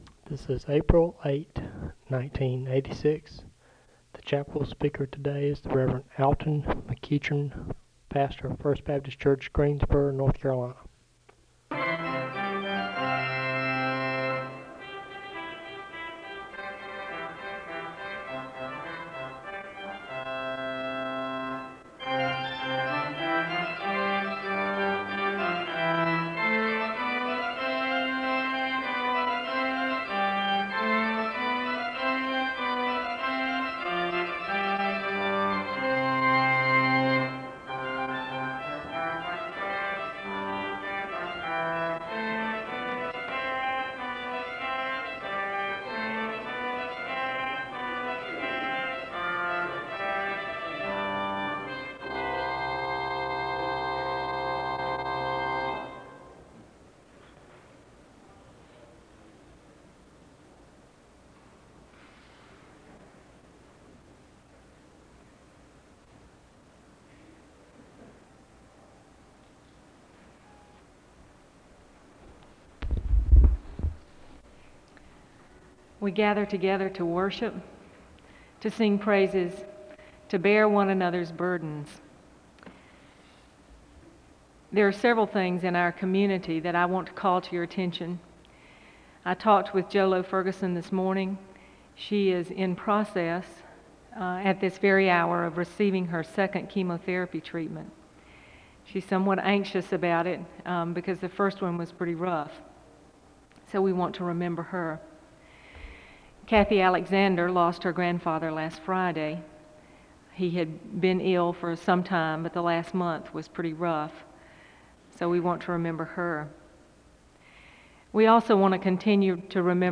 The service begins with organ music (0:00-0:59).
Prayer concerns are shared with the congregation and there is a moment of prayer (1:00-4:08).
SEBTS Chapel and Special Event Recordings SEBTS Chapel and Special Event Recordings